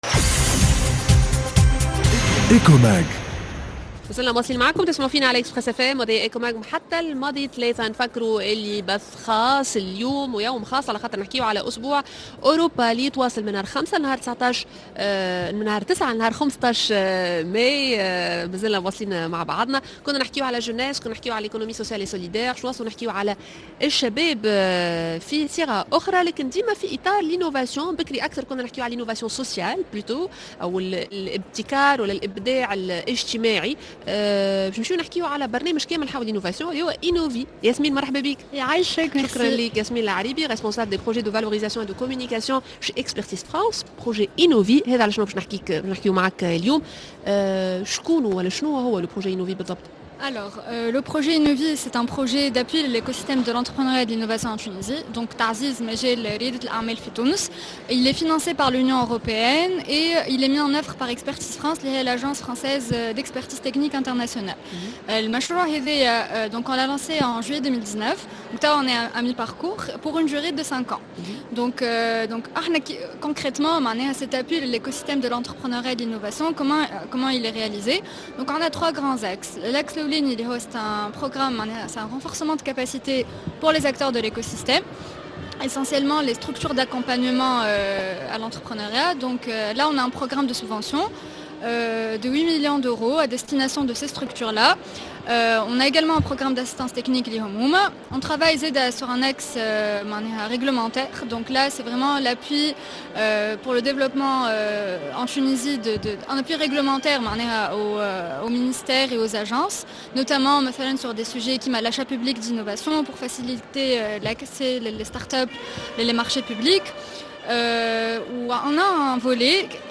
La grande interview